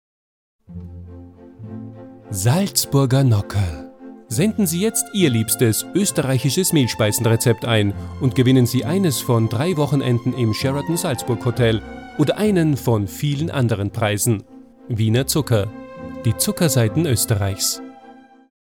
Deutscher Sprecher, Off Sprecher,eigenes Studio vielseitige,warme, durchsetzungsfähige Stimme für Werbung, Dokumentation, Fernsehbeiträge, Trailer,e-learning, Imagefilm, Lyrik und Hörbuch
Sprechprobe: Werbung (Muttersprache):
versatile german voice over artist